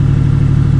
Fast_Idle.wav